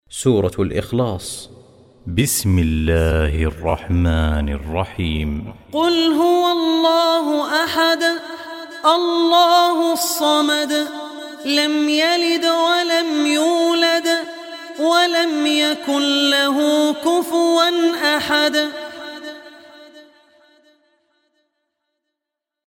Surah Ikhlas Recitation by Abdur Rehman Al Ossi
Surah Ikhlas, listen online mp3 tilawat / recitation in Arabic in the voice of Abdur Rehman Al Ossi.
surah-ikhlas.mp3